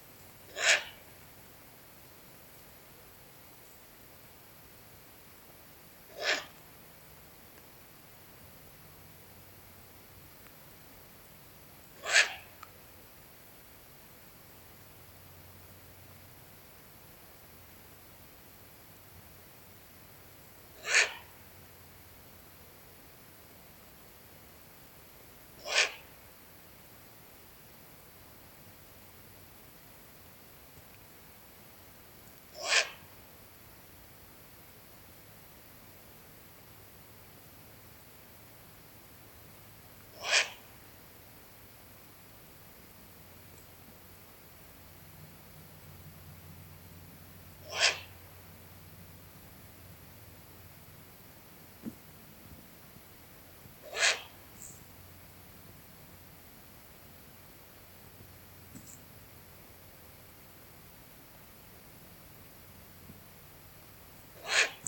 длиннохвостая неясыть, Strix uralensis
СтатусТерриториальное поведение
Примечания/Mātīte